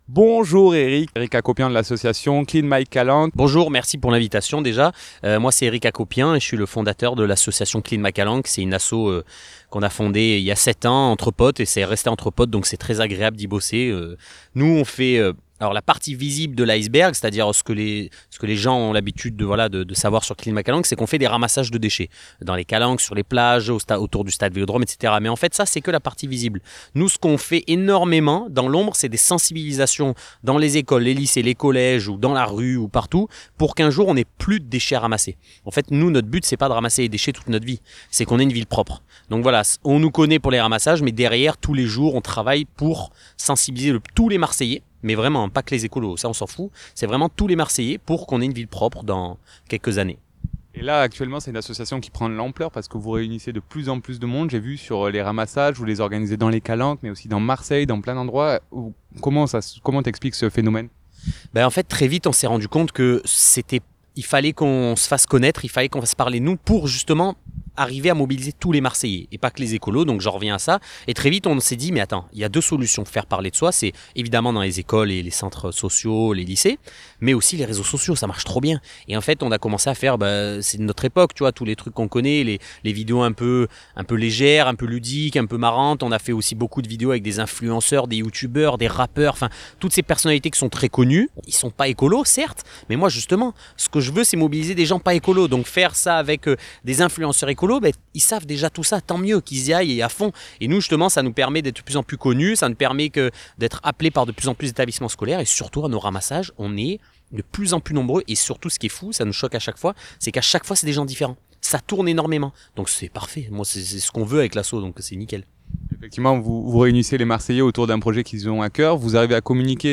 Clean my calanques ITW.mp3 (17.51 Mo)